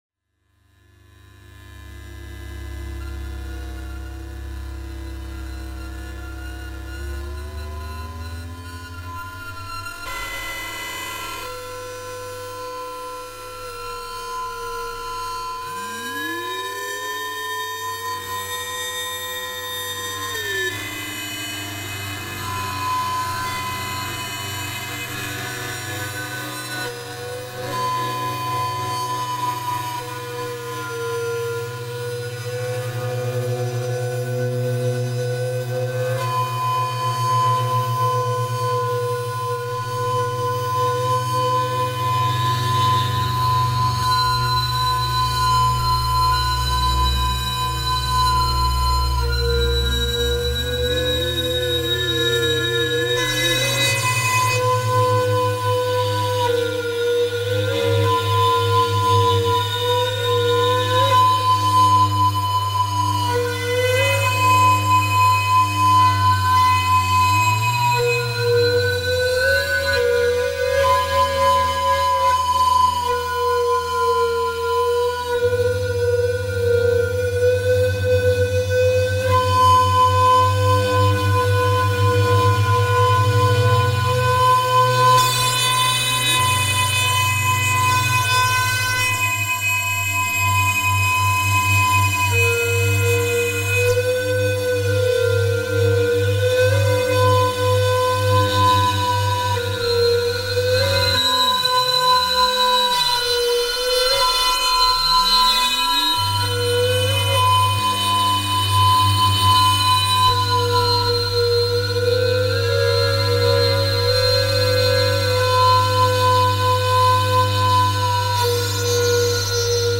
TAPE 02 | tape recorder experiments /// 99’
LARSEN-TAPE-02.mp3